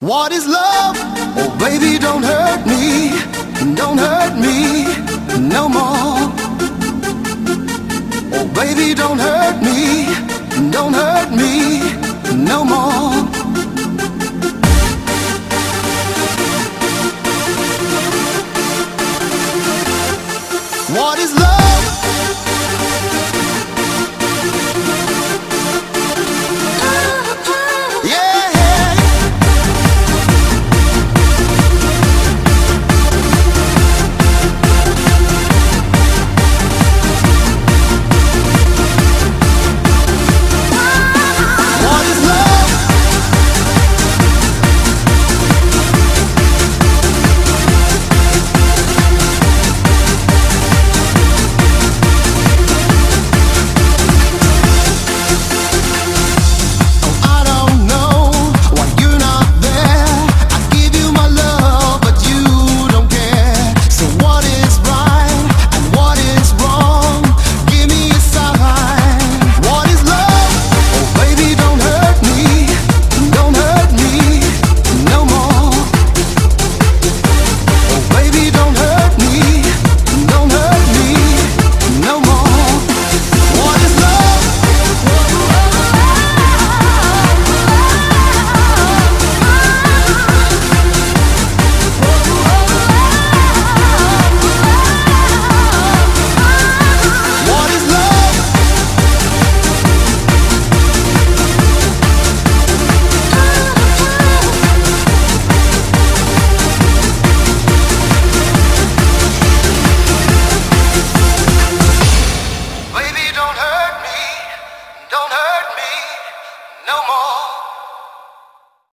BPM138
Audio QualityMusic Cut
it's like a night out in a haunted mansion on Halloween.